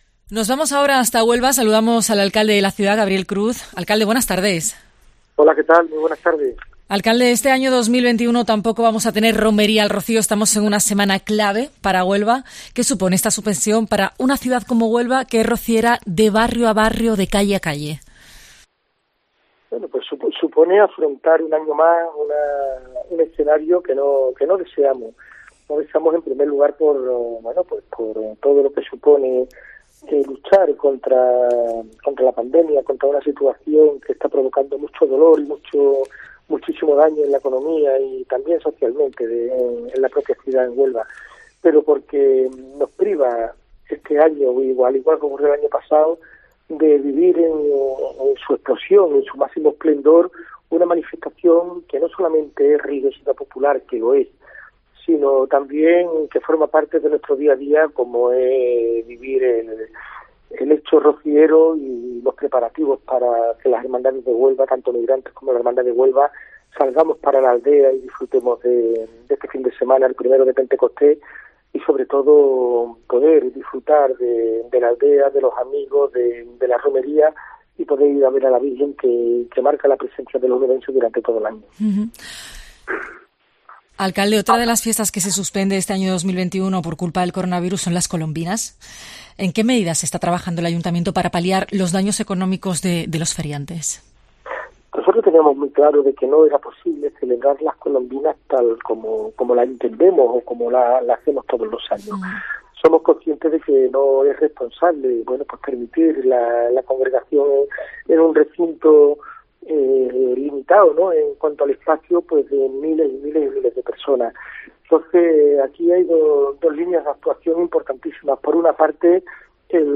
El alcalde de Huelva, Gabriel Cruz, atendió la llamada de La Linterna de COPE Andalucía para abordar cuestiones como la Romería del Rocío, las Colombinas o la presencia en FITUR.